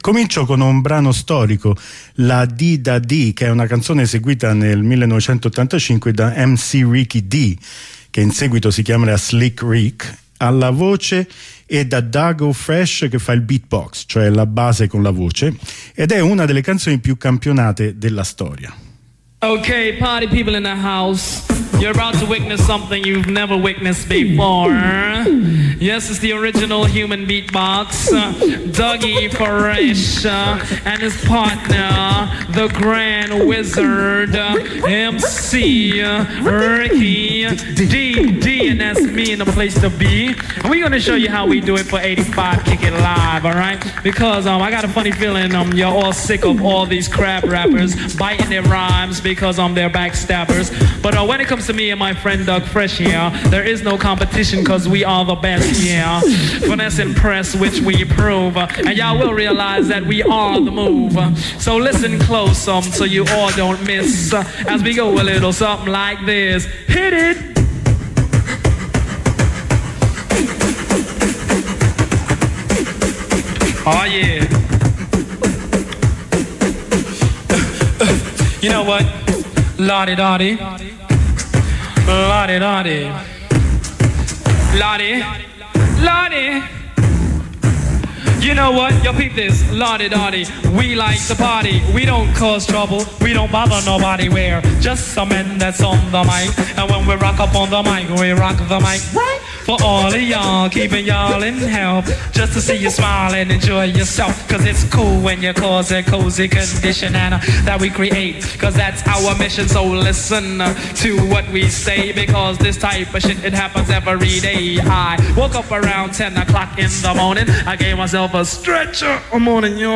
Hip hop n1 OK.ogg